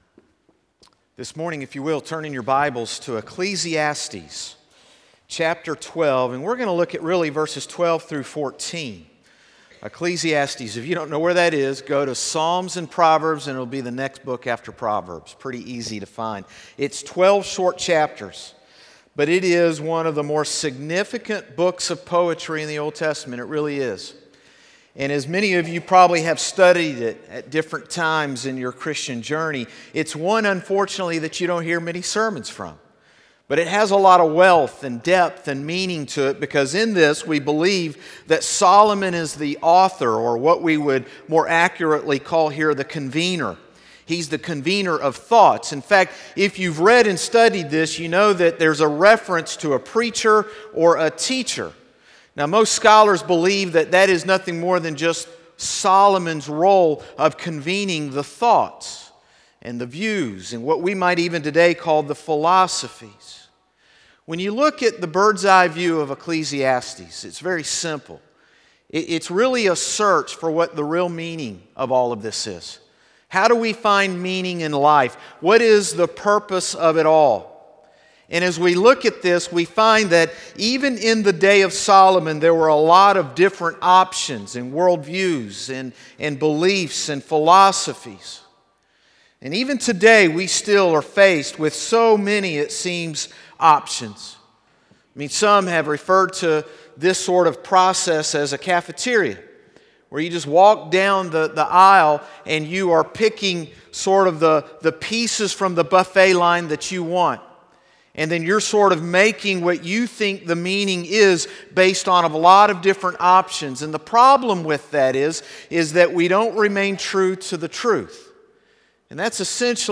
Morning Service - Finding Meaning in Life | Concord Baptist Church
Sermons - Concord Baptist Church